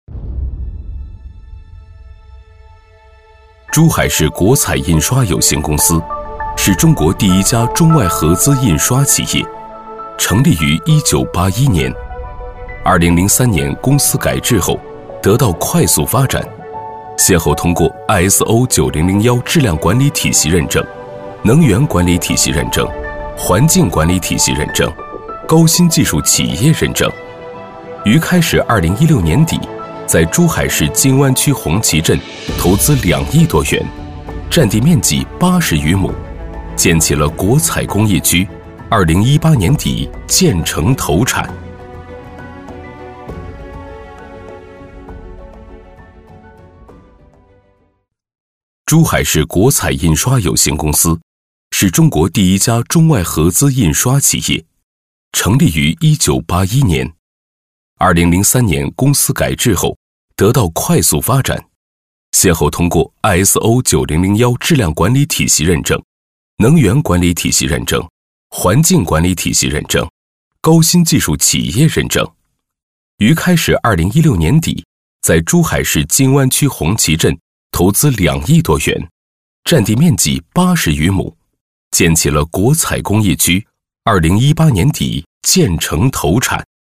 语言：普通话 （31男）
特点：大气浑厚 稳重磁性
风格:浑厚配音